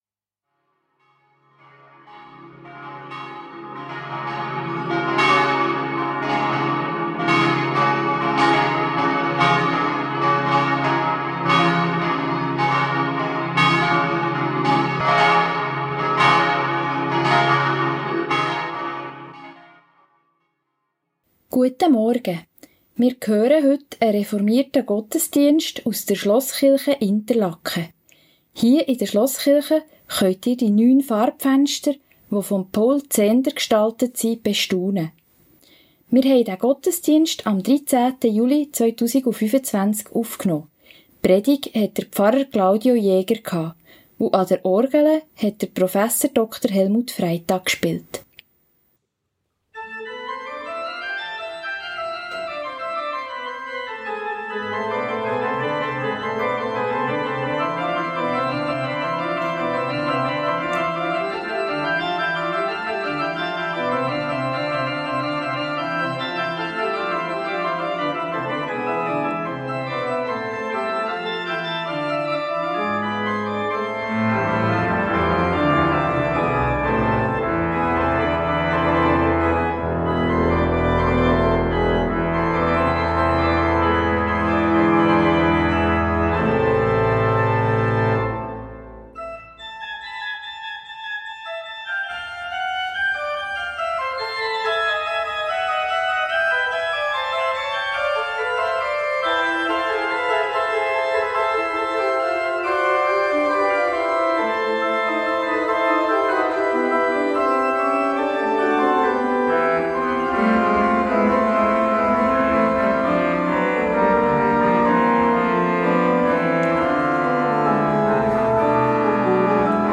Reformierte Schlosskirche Interlaken ~ Gottesdienst auf Radio BeO Podcast